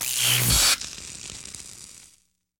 shock.ogg